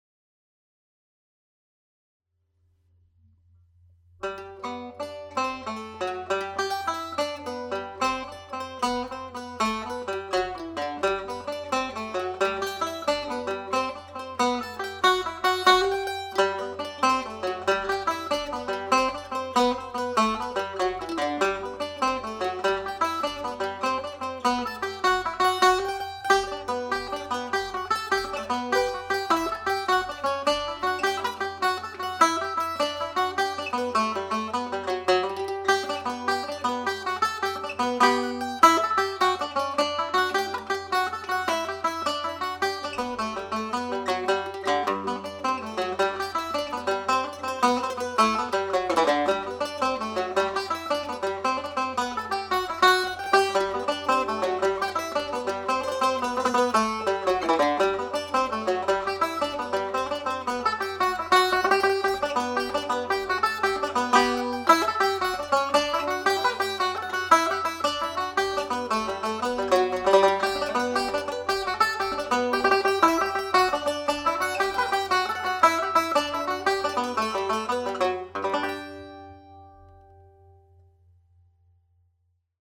Jig (G major)
played at jig speed on both mandolin & banjo